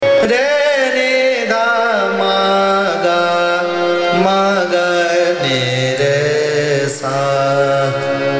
Avaroha‘r N D M G M G N r S